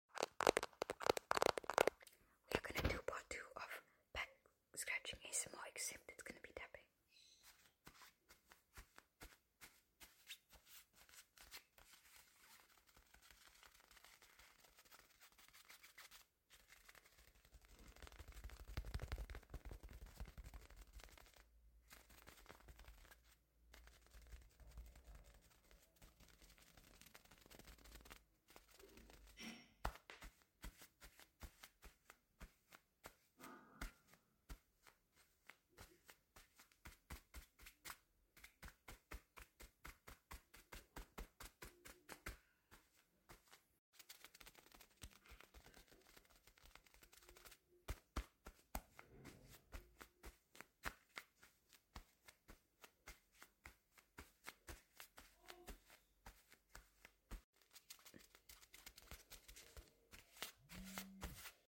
part 2 tapping